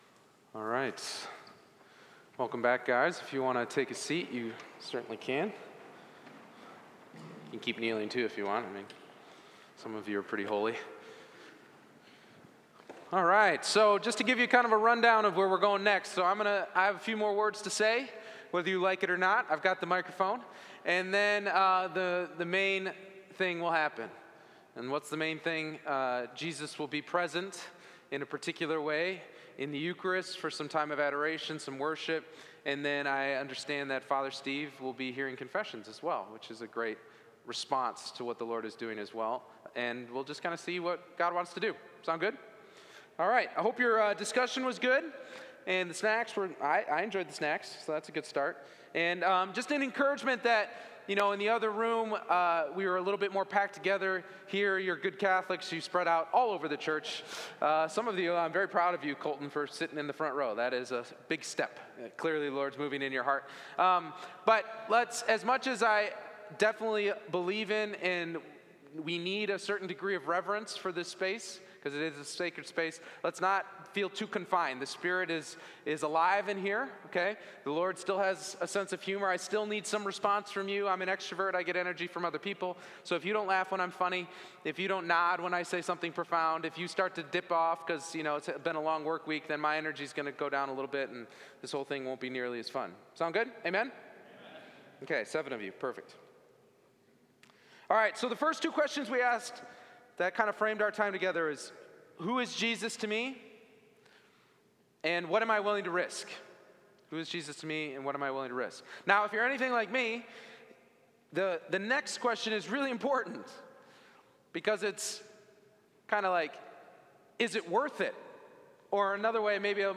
MEN'S RETREAT 2023